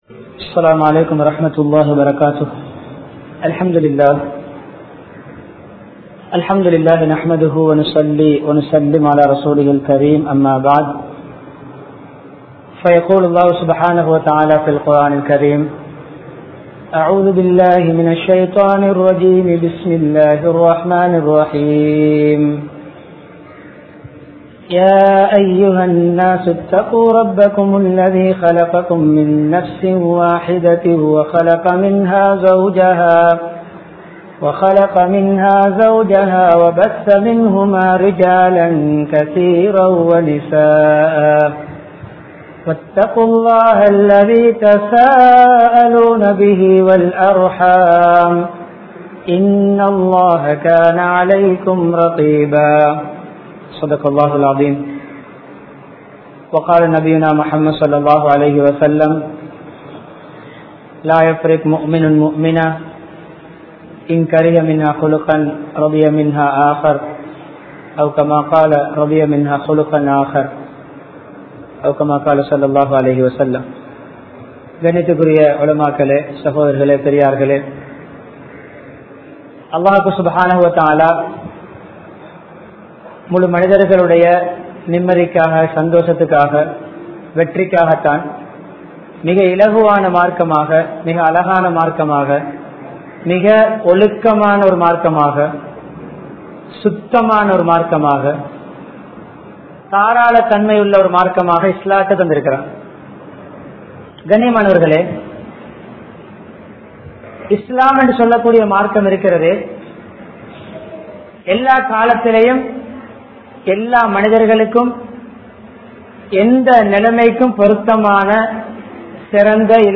Nabi Valiyil Kudumba Vaalkai (நபி வழியில் குடும்ப வாழ்க்கை) | Audio Bayans | All Ceylon Muslim Youth Community | Addalaichenai
Madawela, Wattegama Jumuah Masjith